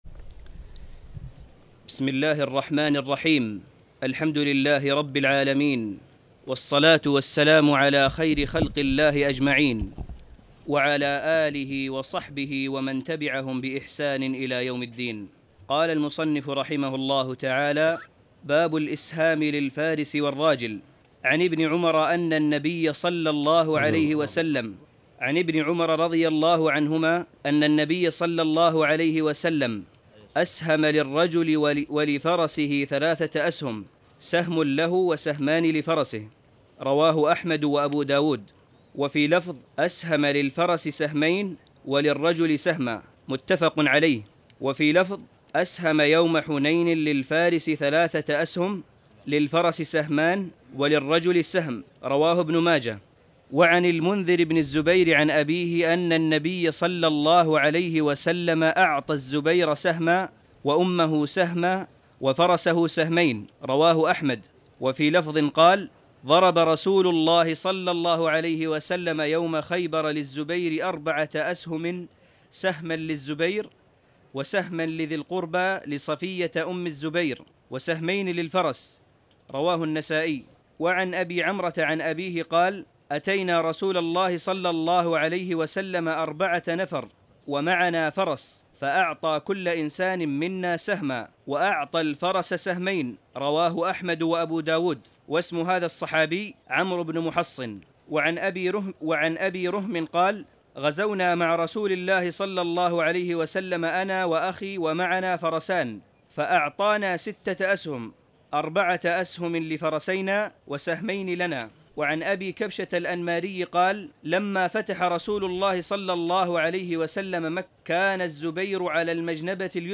تاريخ النشر ١٣ صفر ١٤٣٨ هـ المكان: المسجد الحرام الشيخ: معالي الشيخ أ.د. صالح بن عبدالله بن حميد معالي الشيخ أ.د. صالح بن عبدالله بن حميد باب الإسهام للفارس والراجل